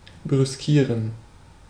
Ääntäminen
Ääntäminen US : IPA : [snʌb]